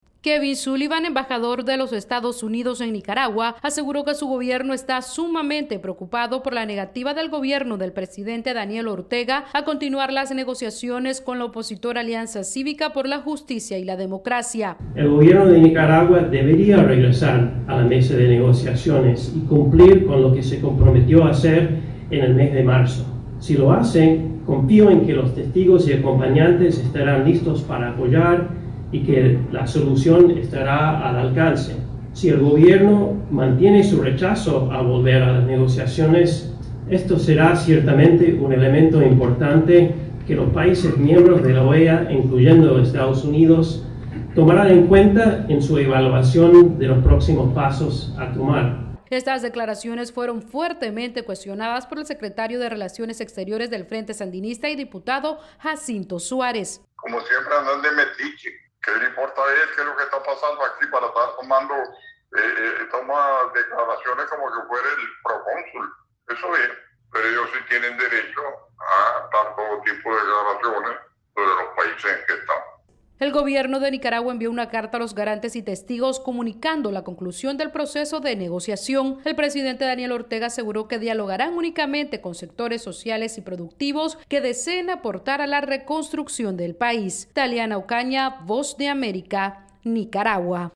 VOA: Informe desde Nicaragua